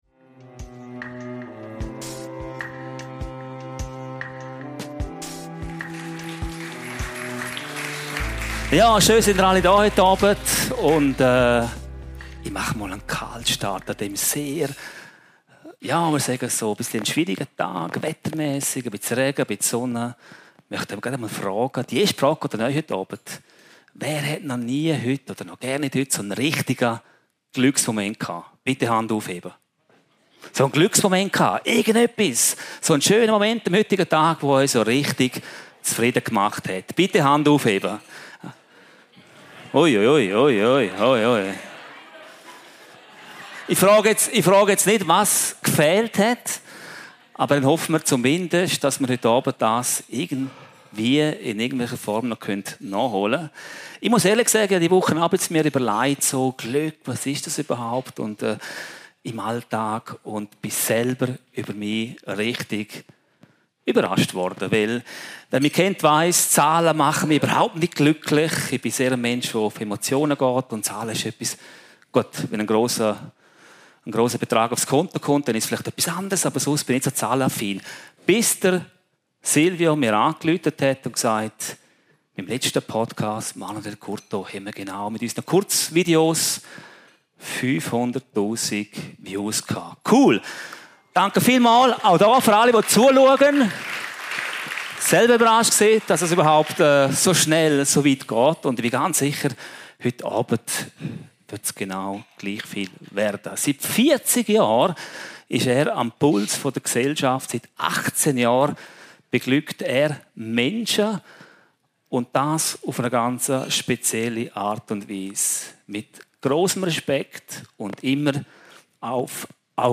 DEEP DIVE – der Live Podcast aus dem From Heaven Podcast
Aufgezeichnet im From Heaven, Bad Ragaz